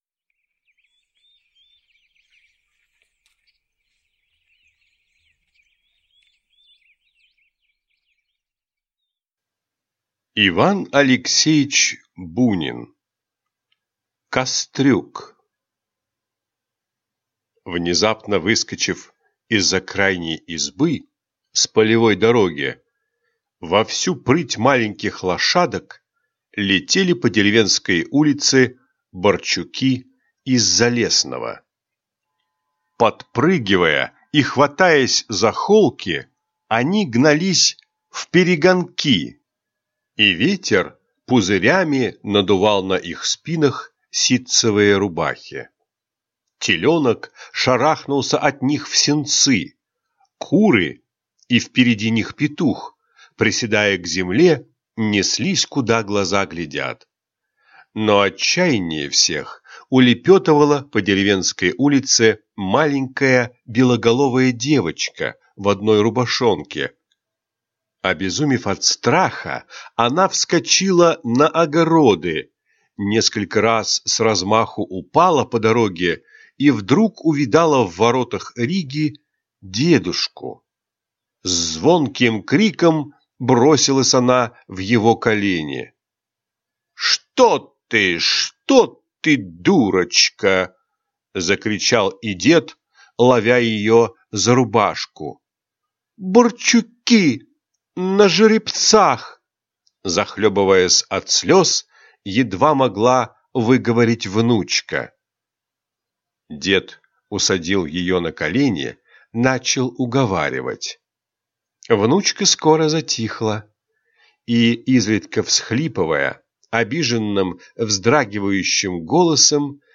Аудиокнига Кастрюк | Библиотека аудиокниг